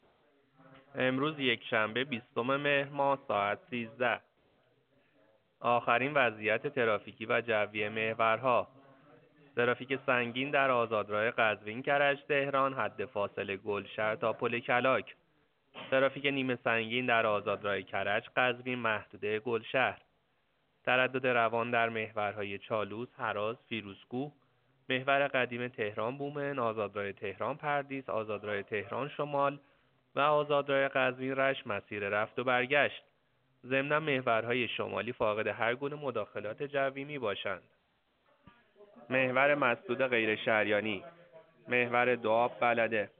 گزارش رادیو اینترنتی از آخرین وضعیت ترافیکی جاده‌ها ساعت ۱۳ بیستم مهر؛